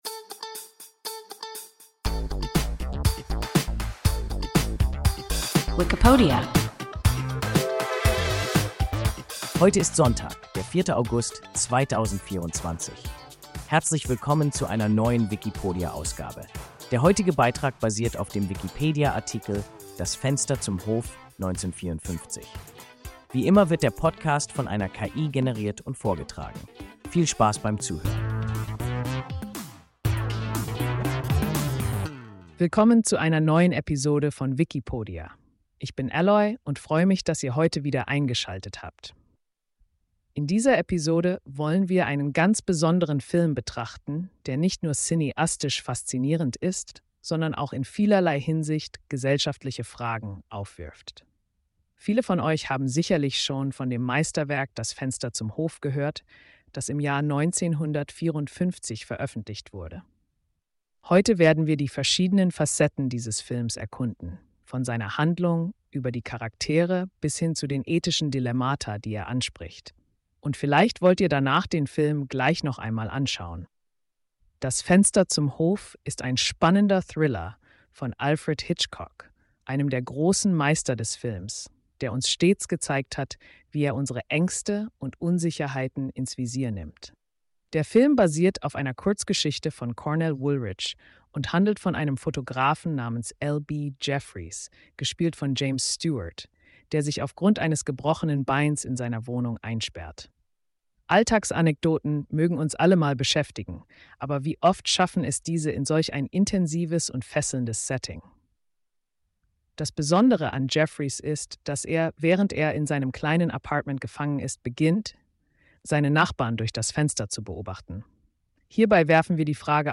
Das Fenster zum Hof (1954) – WIKIPODIA – ein KI Podcast